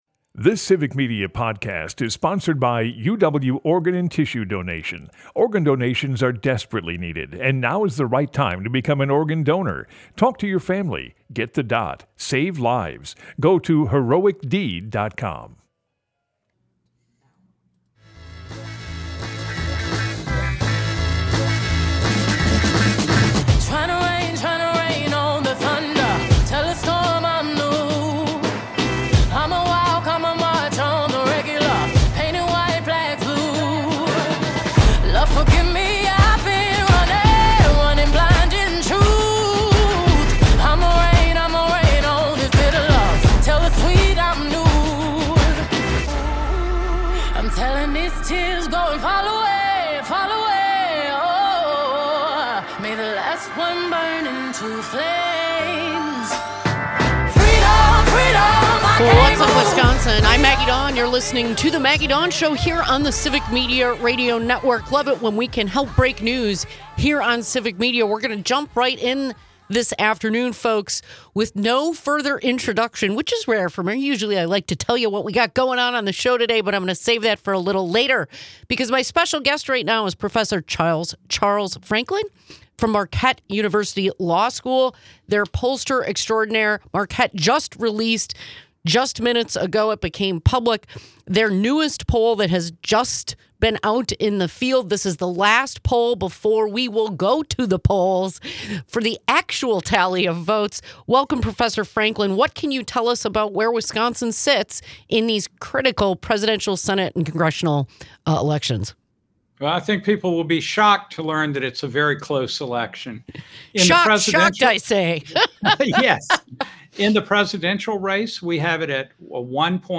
take calls from concerned individuals regarding the tax policies and extreme radical agendas of the republican party